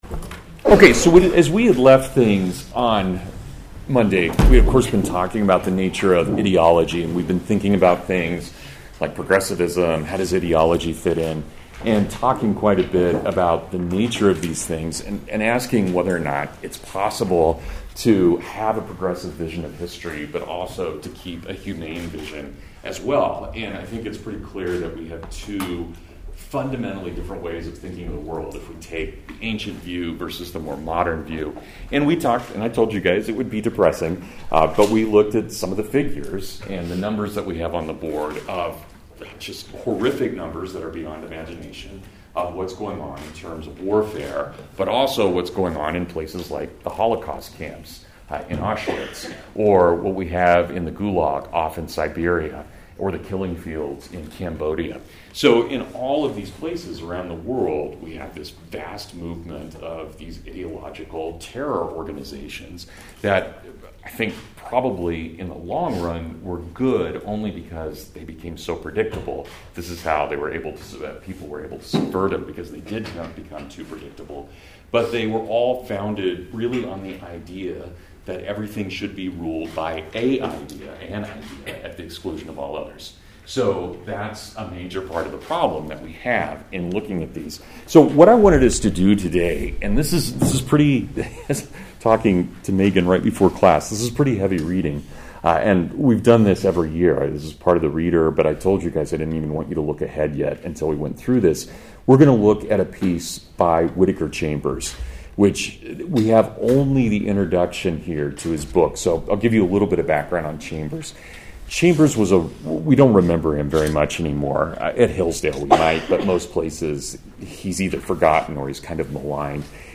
Horrors of Communism (Full Lecture)